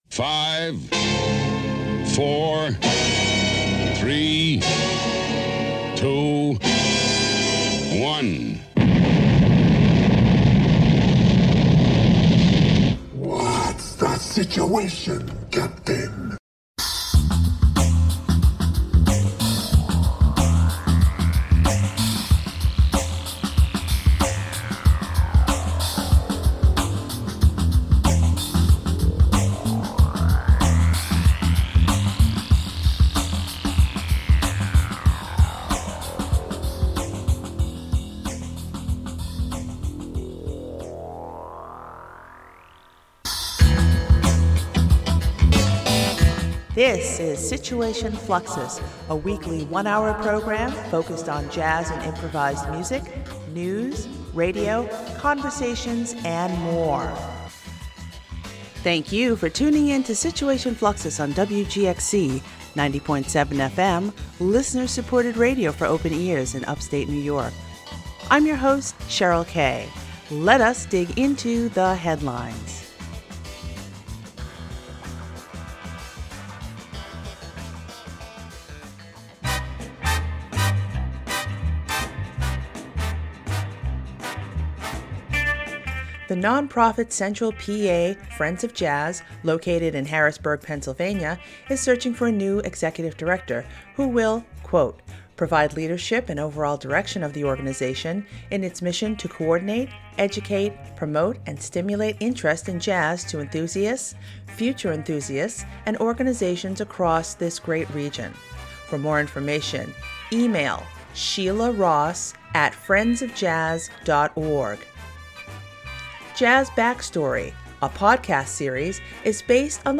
Situation Fluxus is a weekly one-hour program focused on jazz and improvised music, news, radio, conversations, and more.